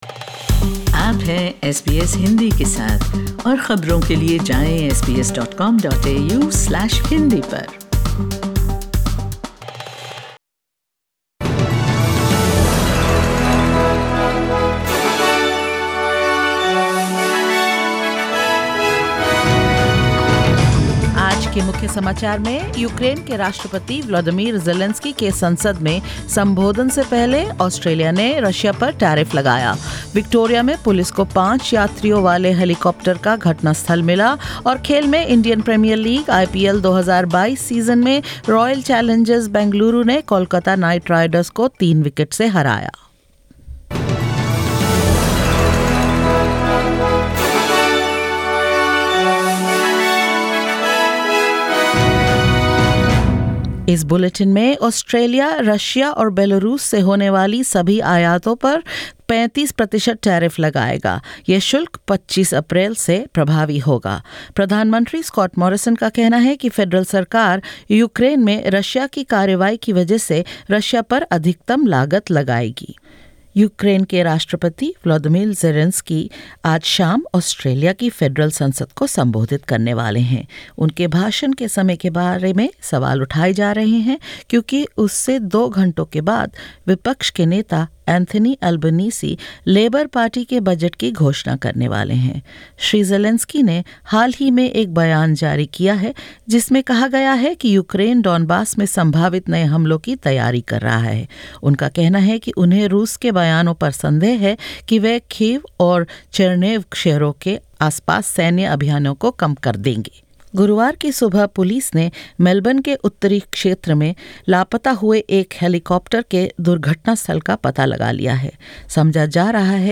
SBS Hindi News 31 March 2022: Australia raises tariffs on imports from Russia and Belarus